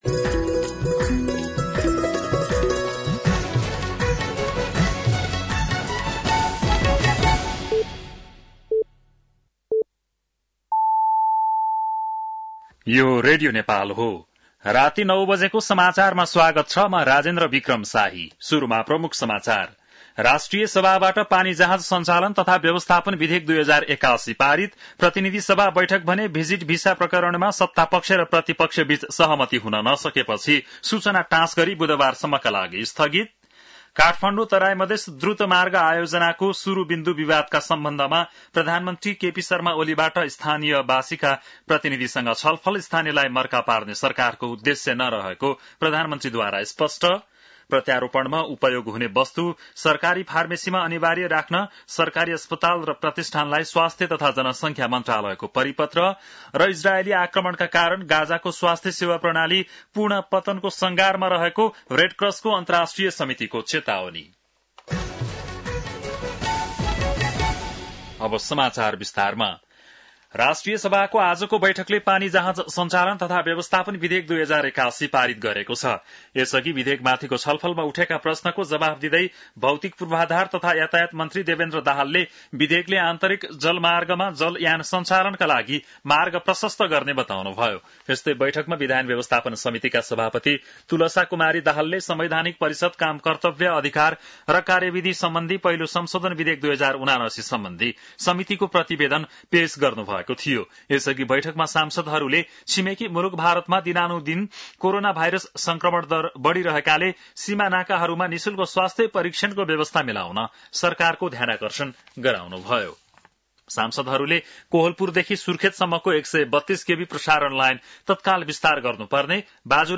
बेलुकी ९ बजेको नेपाली समाचार : २६ जेठ , २०८२
9-PM-Nepali-NEWS.mp3